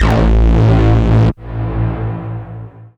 tekTTE63022acid-A.wav